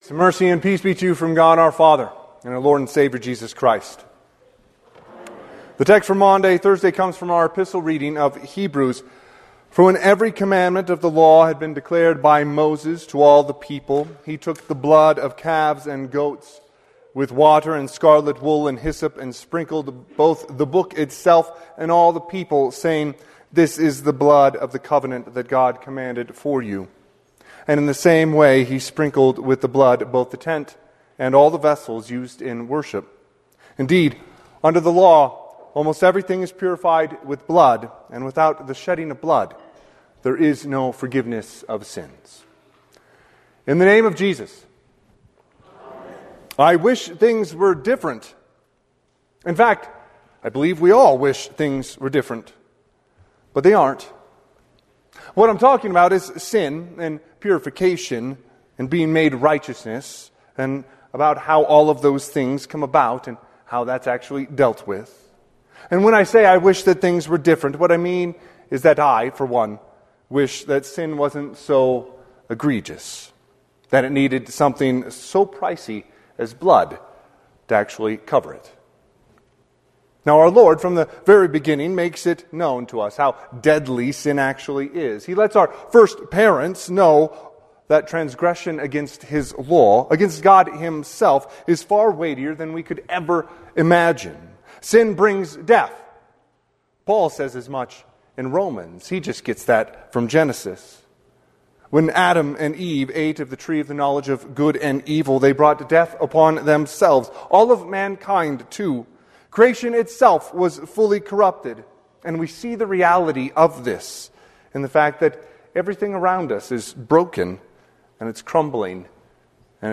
Sermon – 4/2/2026 - Wheat Ridge Evangelical Lutheran Church, Wheat Ridge, Colorado
Holy Thursday